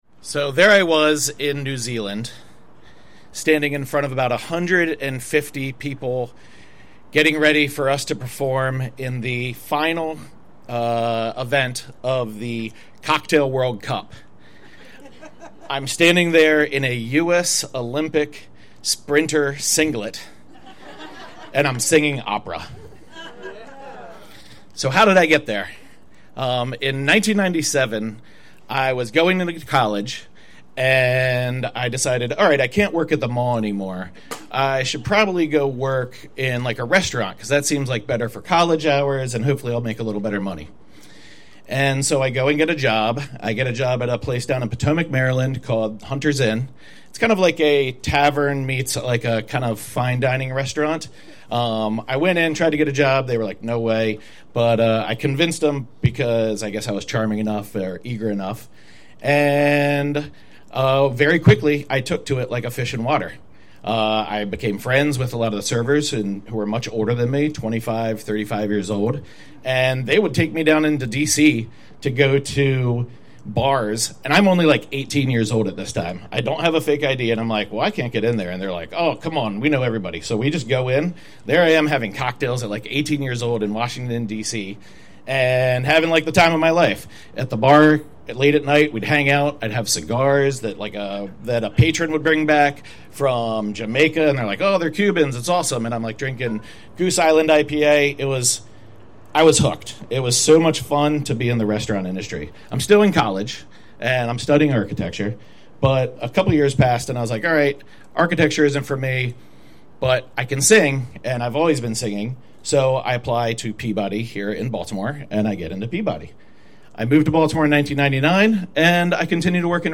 The Stoop Storytelling Podcast